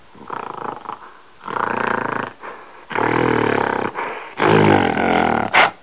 جلوه های صوتی
دانلود صدای حیوانات جنگلی 30 از ساعد نیوز با لینک مستقیم و کیفیت بالا